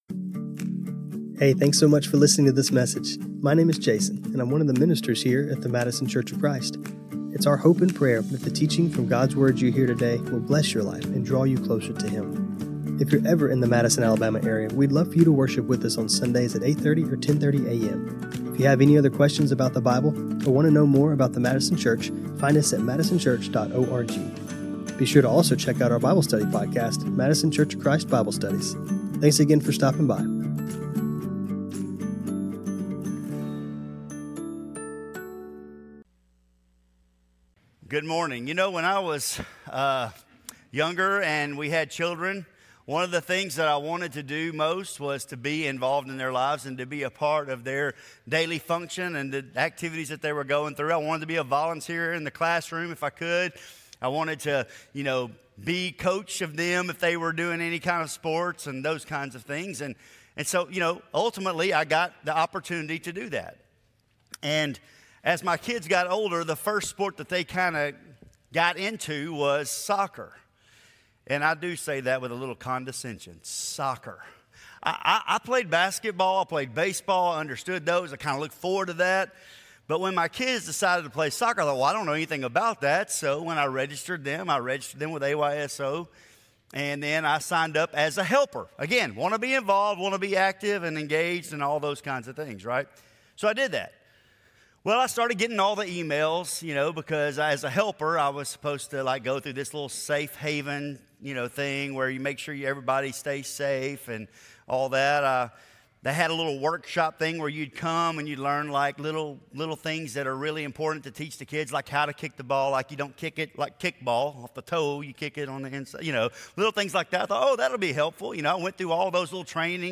Scripture Reading: Matthew 7:13-23 This sermon was recorded on Feb 8, 2026.